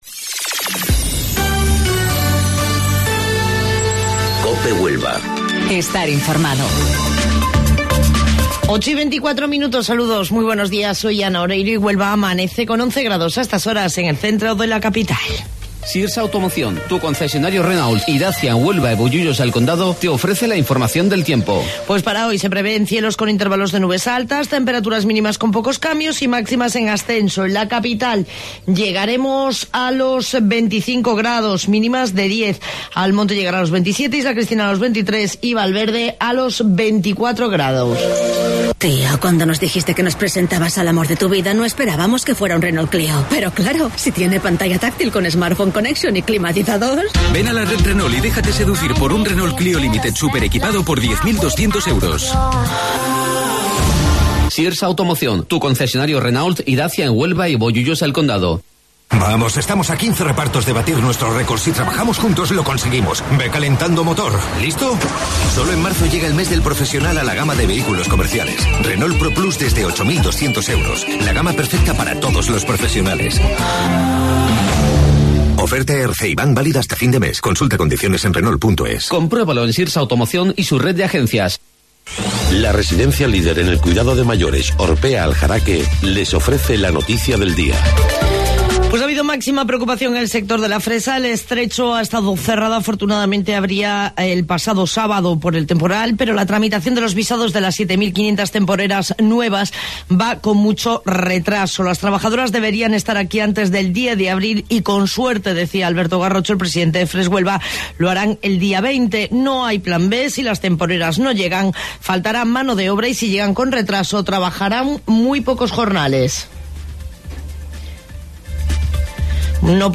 AUDIO: Informativo Local 08:25 del 25 de Marzo